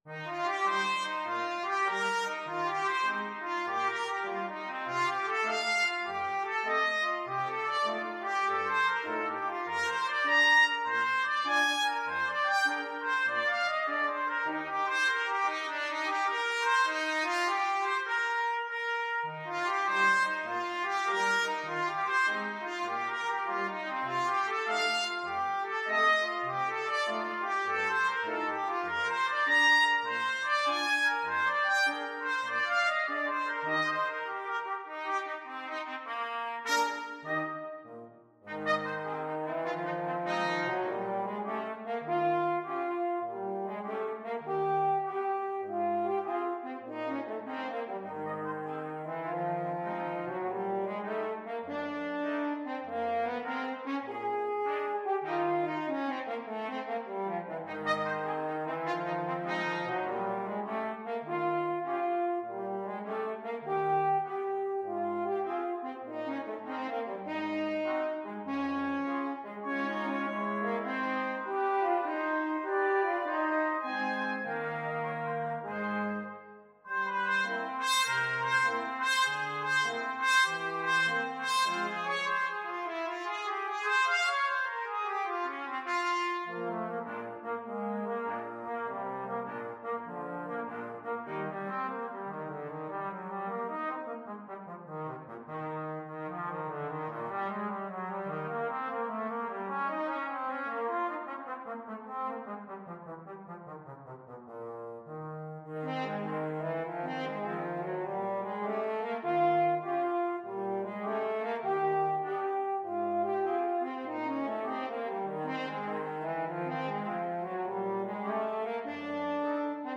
Free Sheet music for Brass Quartet
Trumpet 1Trumpet 2French HornTrombone
4/4 (View more 4/4 Music)
Eb major (Sounding Pitch) (View more Eb major Music for Brass Quartet )
Lightly = c. 100
Brass Quartet  (View more Advanced Brass Quartet Music)
Jazz (View more Jazz Brass Quartet Music)